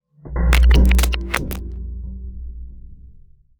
UI_SFX_Pack_61_51.wav